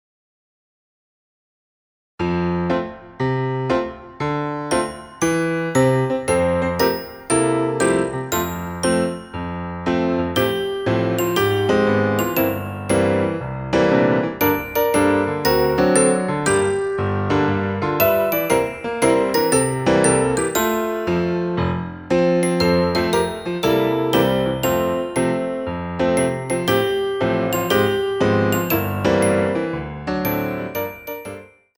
Tendu II